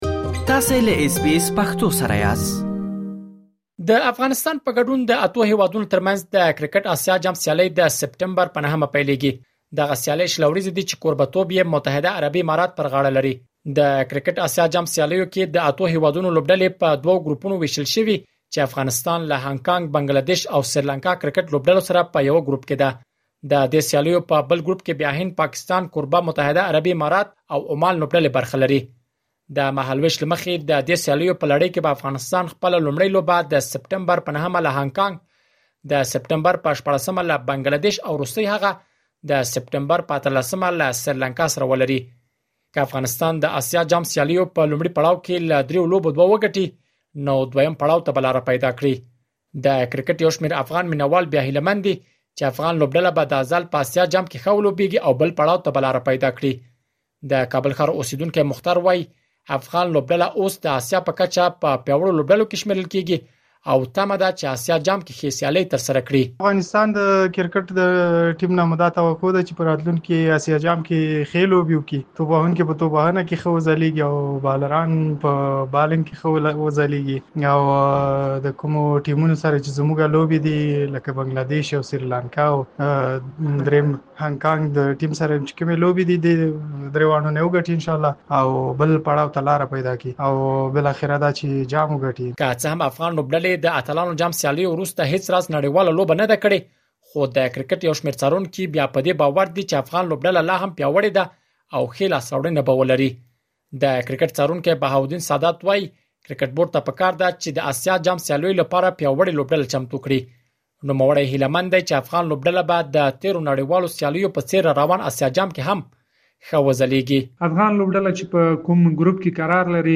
سره له دې چې د افغانستان، پاکستان او متحده عربي اماراتو ترمنځ لوبلړۍ له اسیا جام مخکې ترسره کېږي خو تر اوسه د دغې لوبلړۍ د ترسره کېدو نېټه معلومه نه ده. مهرباني وکړئ لا ډېر معلومات په رپوټ کې واورئ.